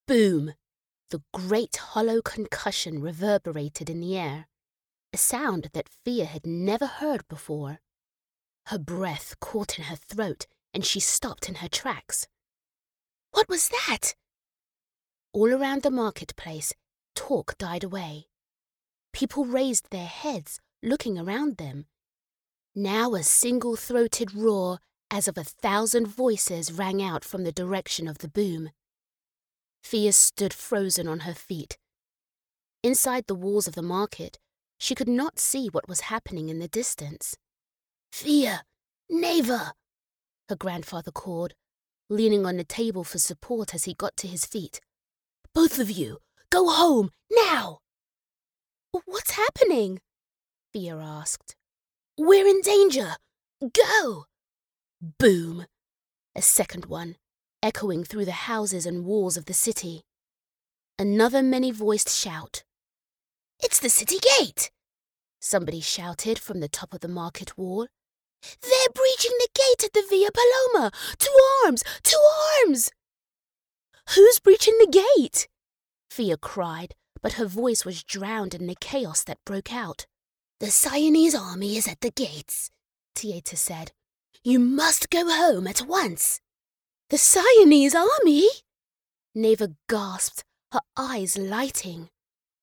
Mujer
Inglés (Reino Unido)
Audiolibros
Me aseguro de que los proyectos de los clientes sean de la más alta calidad de sonido utilizando un estudio Whisper Room con tratamiento acústico, aprobado por ingenieros de sonido y con equipos de última generación.